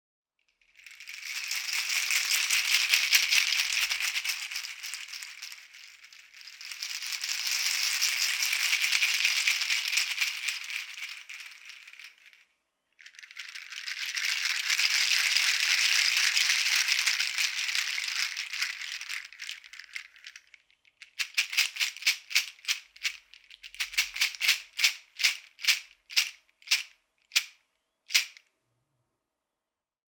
Aufgrund seines eher ruhigen und weichen Klanges eignet sich der Meinl Sonic Energy Pala Rod Shaker hervorragend für mystische, leise Einsätze.